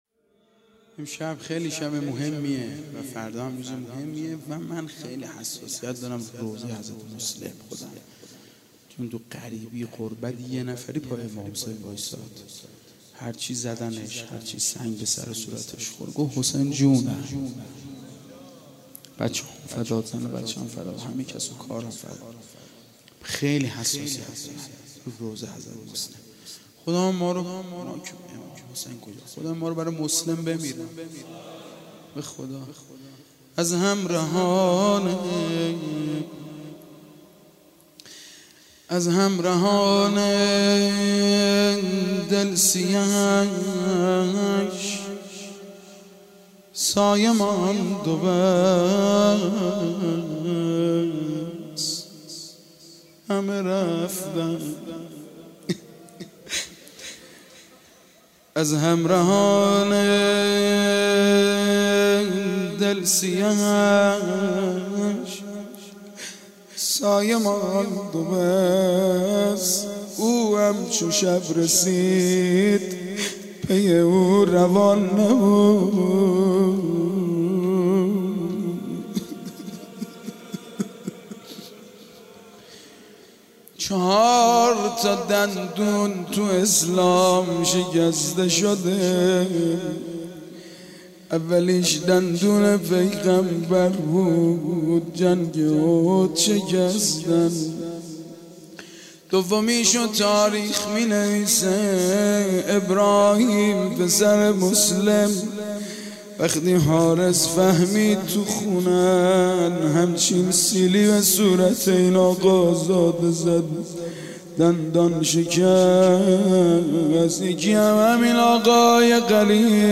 09-Rozeh-2.mp3